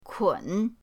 kun3.mp3